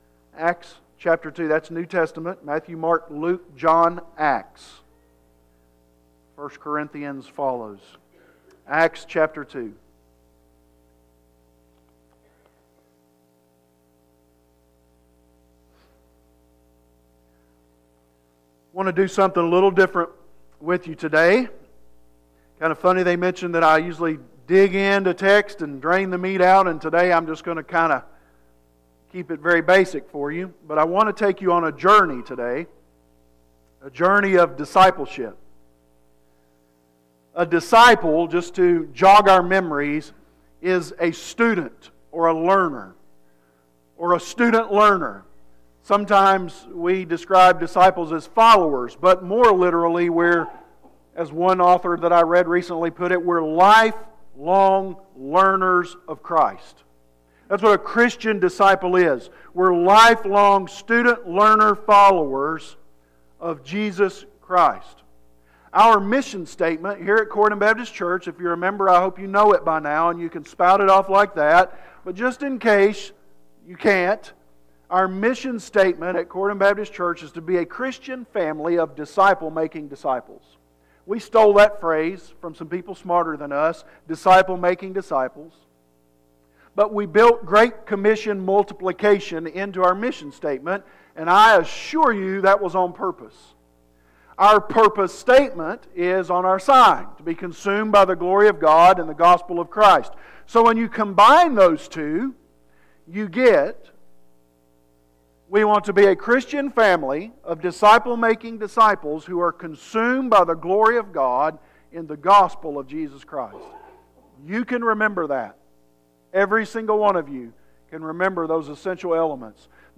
Sermon Series - Corydon Baptist Church - A Christian Family of Disciple Making Disciples